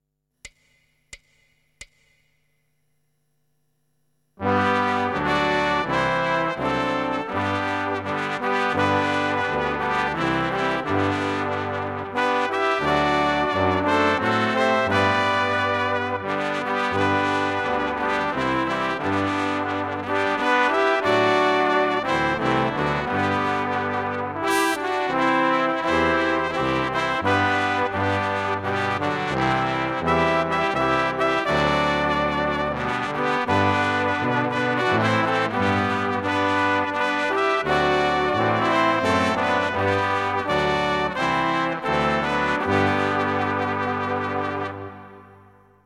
Besetzung: Blasorchester
Choräle/Grablieder/Hymnen/Trauermärsche/Straßenmärsche.